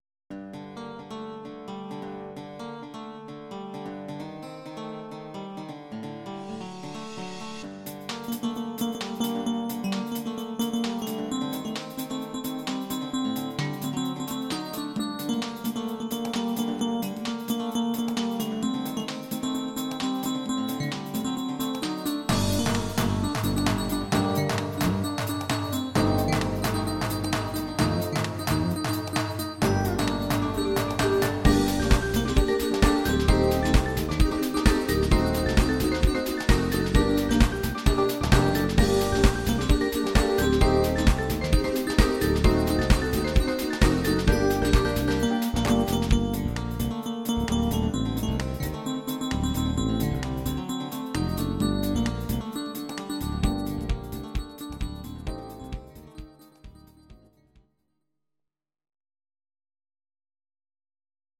Audio Recordings based on Midi-files
Ital/French/Span, 1980s